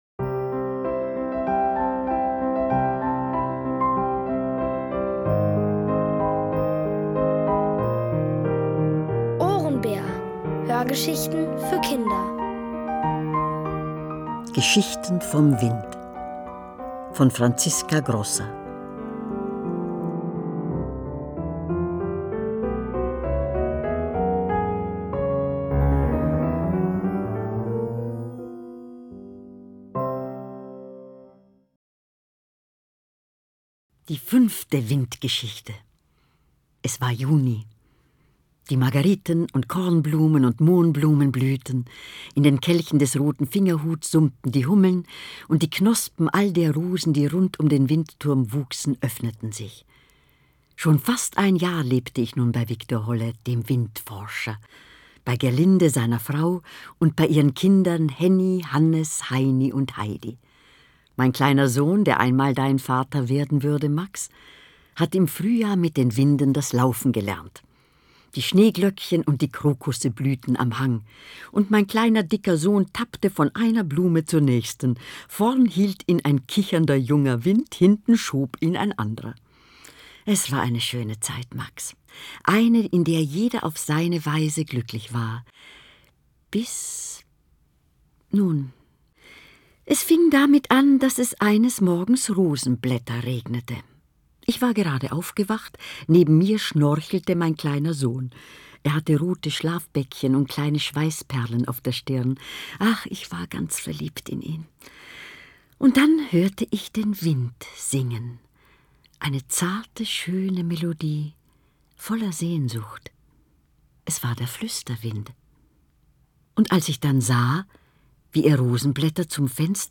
Von Autoren extra für die Reihe geschrieben und von bekannten Schauspielern gelesen.
Es liest: Elfriede Irrall.